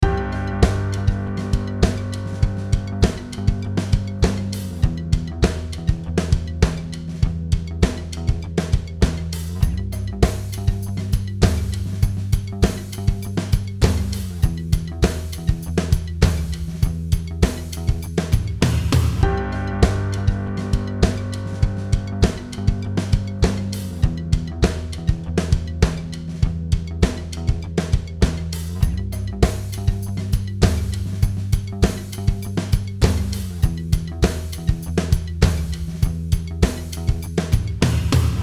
心地よいベースの振動とドラムのタイトなリズムが融合した
このテンポ100の着信音は、モダンでスタイリッシュなサウンドが特徴。